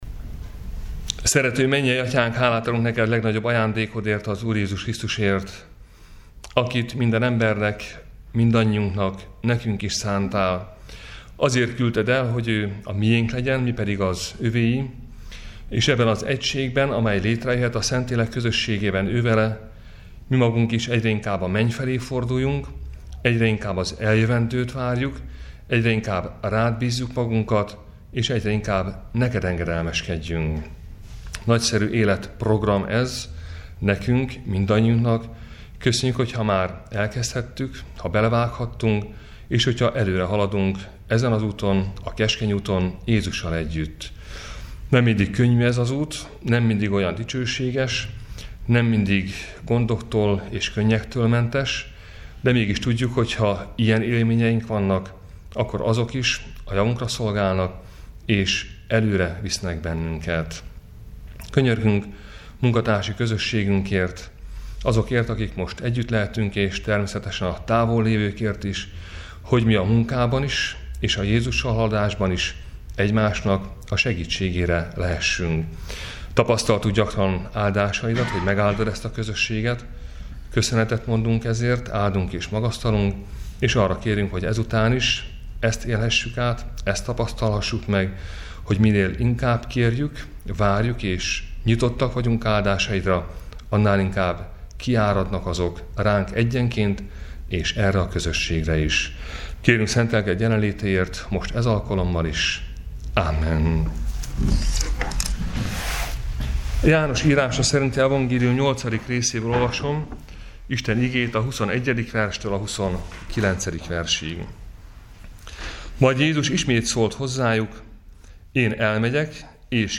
Áhítat, 2019. február 20.